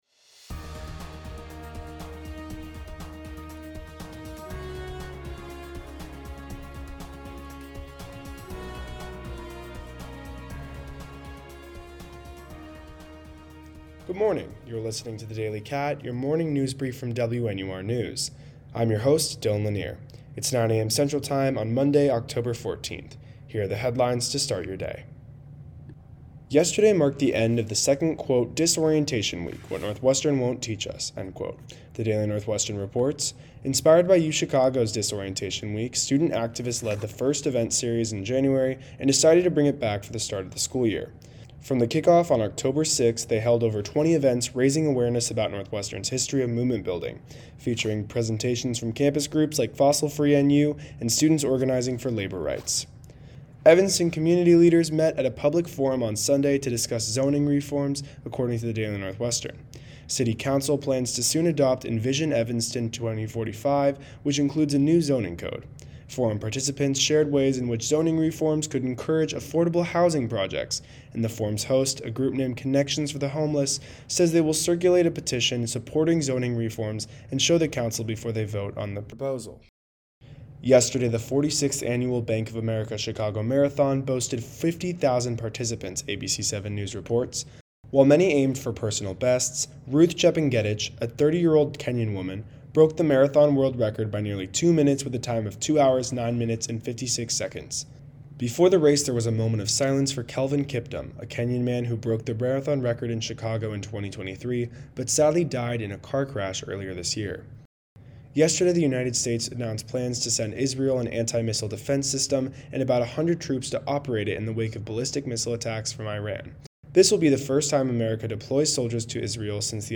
WNUR News broadcasts live at 6 pm CST on Mondays, Wednesdays, and Fridays on WNUR 89.3 FM